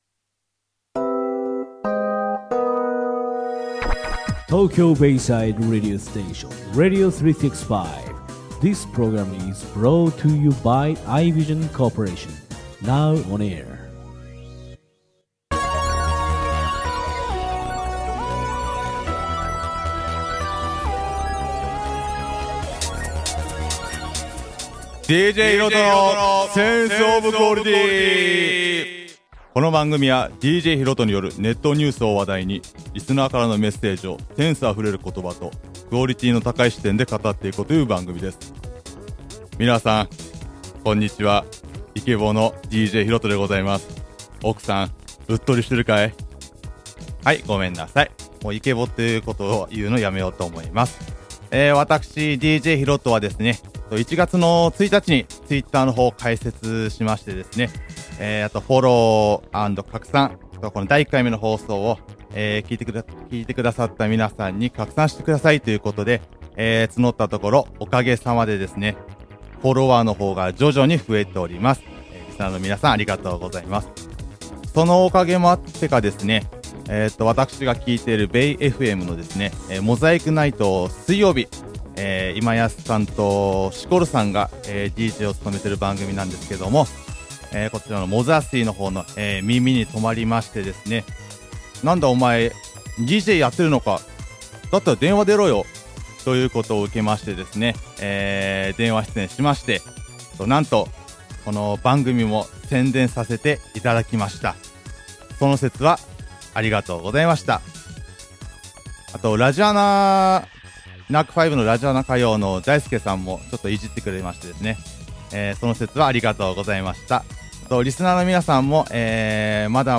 風邪も治り、イケボ全開？でお送りしました。